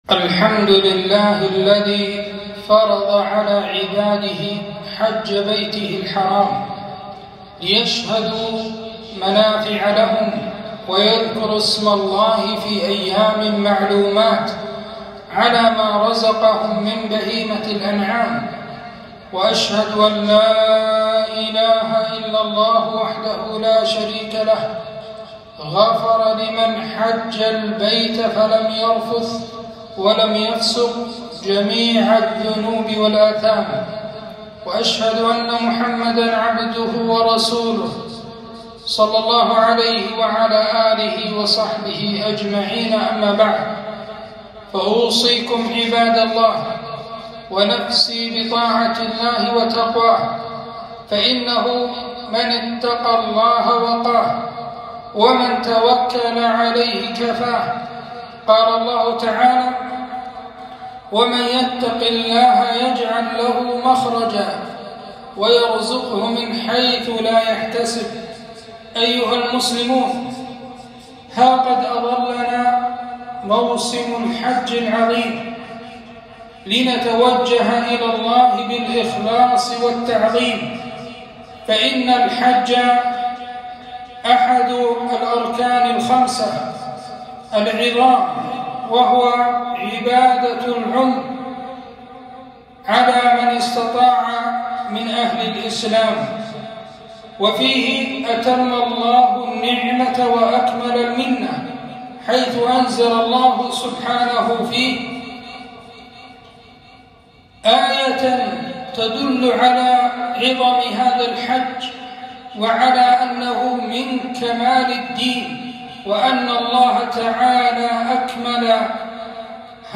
خطبة - الحج فضائل وأحكام